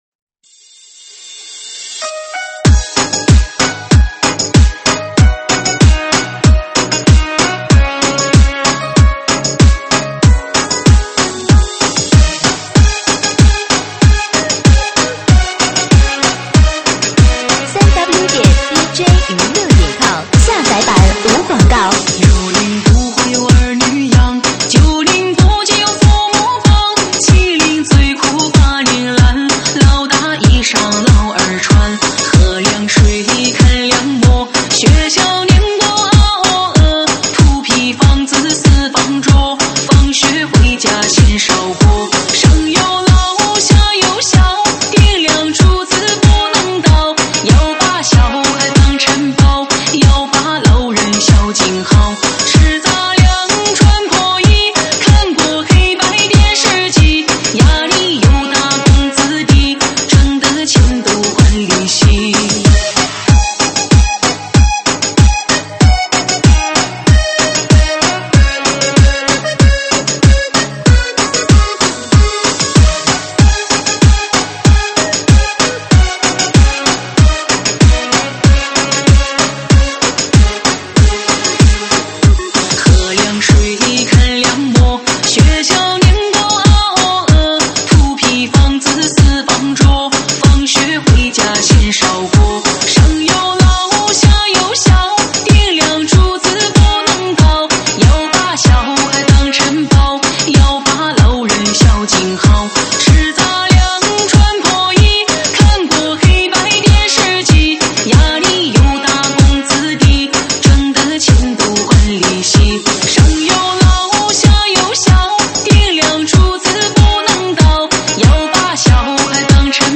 舞曲类别：伤感情歌